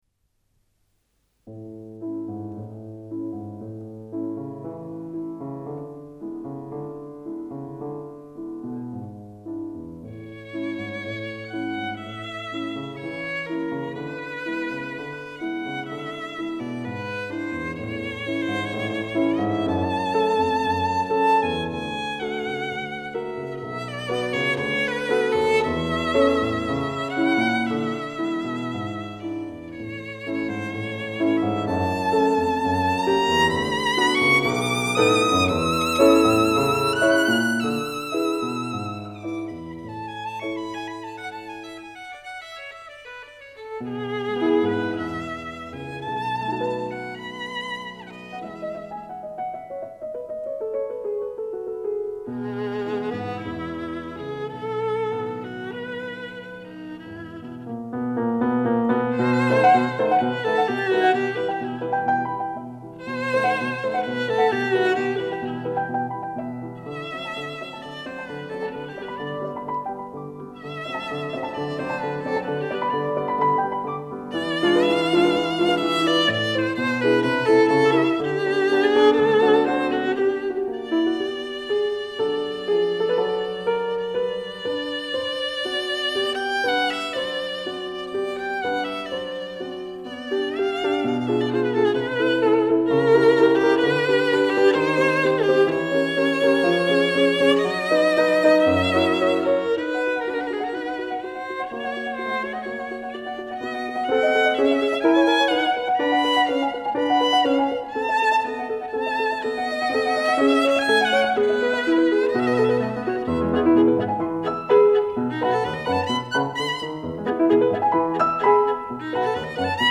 Violin
piano
recorded at Sendesaal des Karlsruher Studios, South German Radio